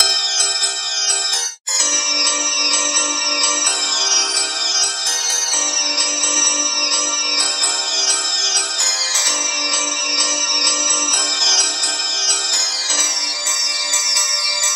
嘻哈合成器
Tag: 130 bpm Hip Hop Loops Synth Loops 2.48 MB wav Key : Unknown